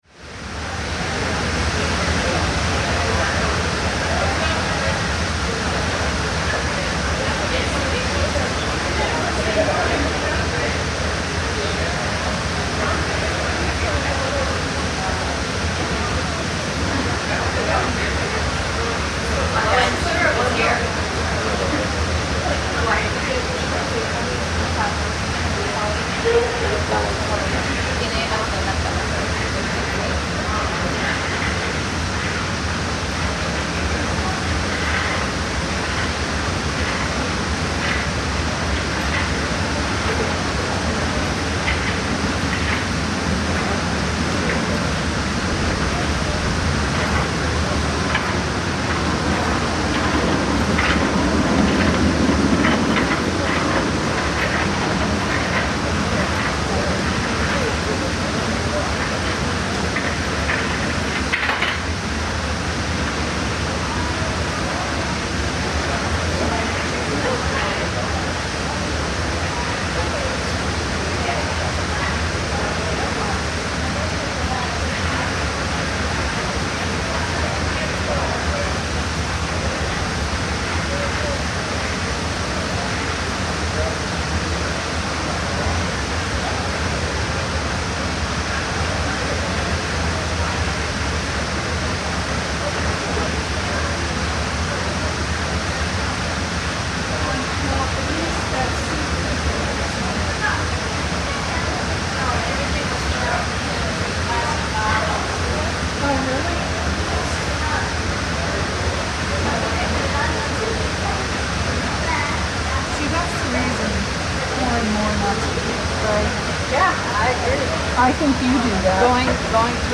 In the recording, you can hear the sound of people chatting and laughing, (I swear I hear someone happily say "Jamiroquai!" towards the end), heels clacking as some choose to walk instead of using the walkway, and beginning at the eight-minute mark you can hear a child say "wow!" a few times.